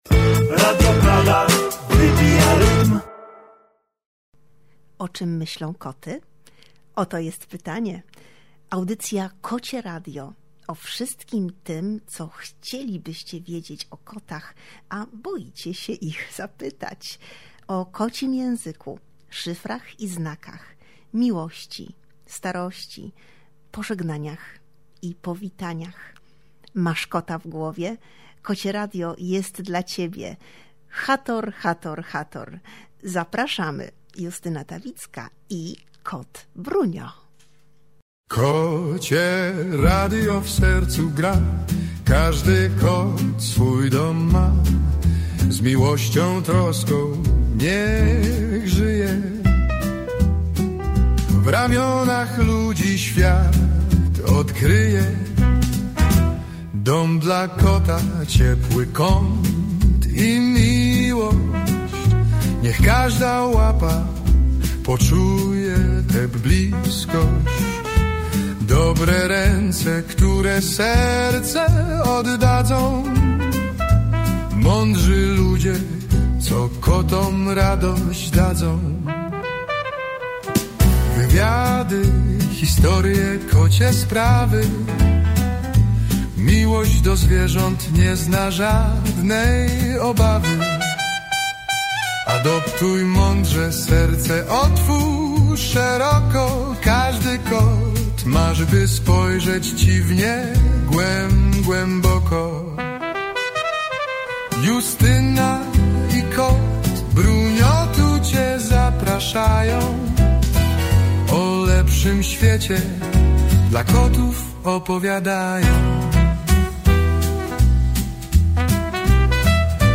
To będzie rozmowa na trudny temat, z którym niestety czasem musimy się zmierzyć…Porozmawiamy o pożegnaniach naszych zwierzęcych przyjaciół, o tym jak się na to przygotować – o ile w ogóle jest to możliwe, jak godnie pożegnać, jak upamiętnić, gdzie szukać wsparcia w żałobie i jak wesprzeć w tym siebie i innych.